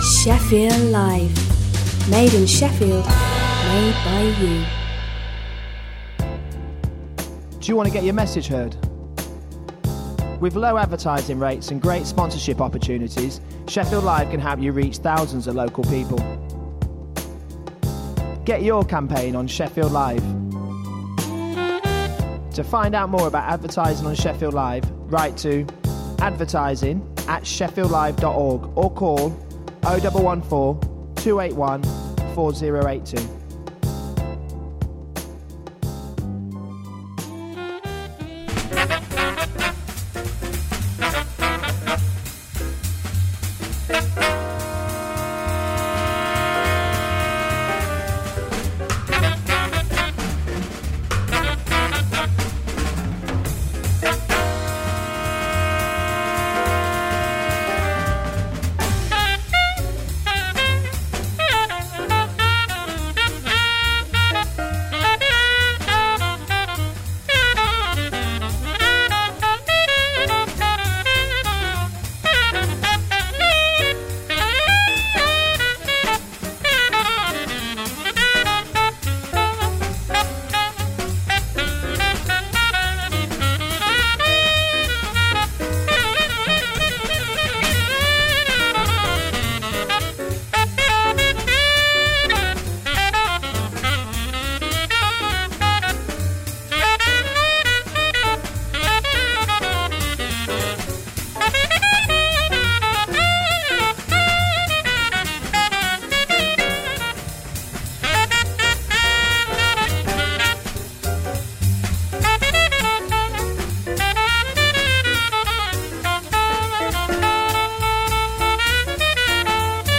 An extensive weekly exploration of modern jazz; be-bop, hard bop, West Coast, Latin, bossa nova, vocals, fusion and soul.